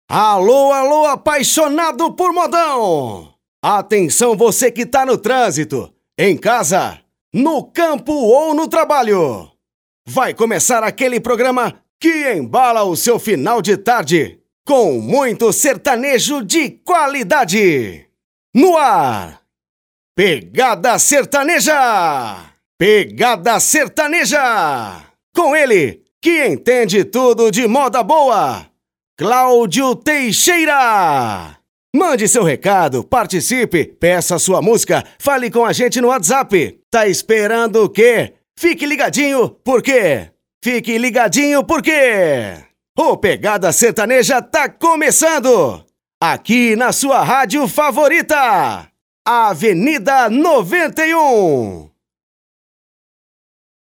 ABERTURA DE PROGRAMA SERTANEJO ANIMADO OFF: